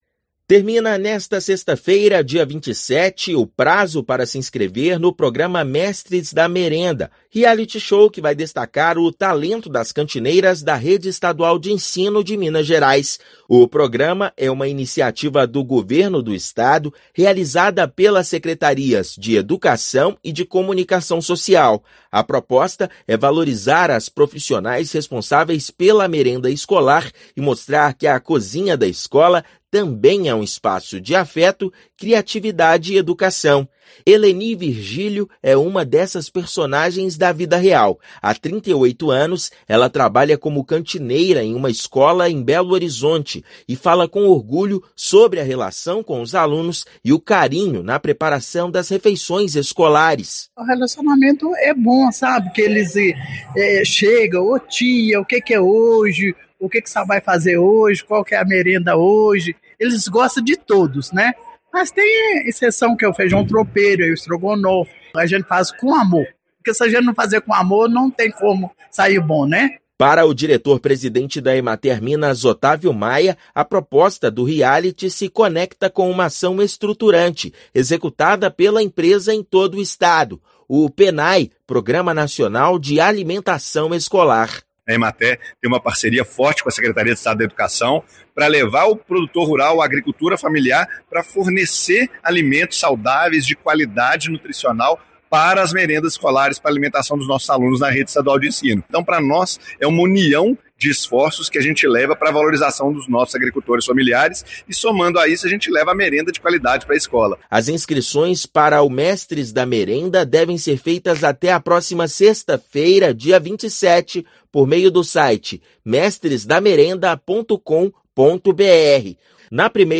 [RÁDIO] Inscrições para o programa Mestres da Merenda vão até sexta-feira (27/6)